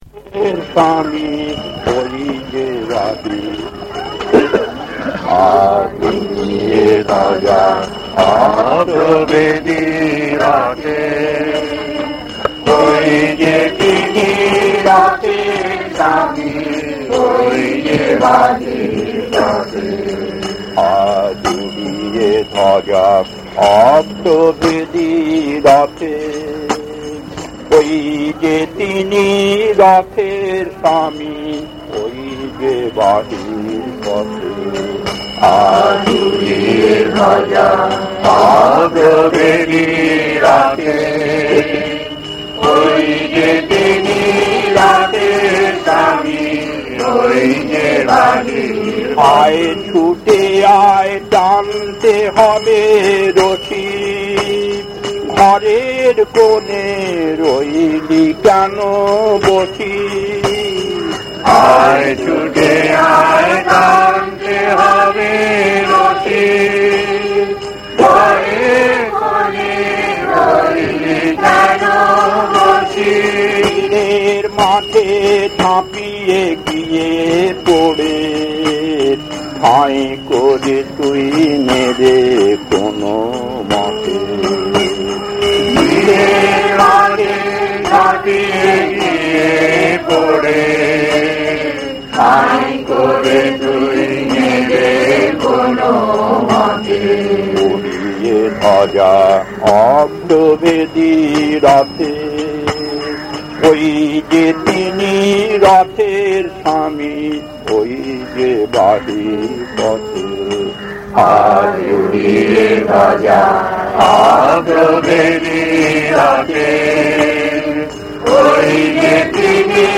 Kirtan A2-1 Puri 1981 1.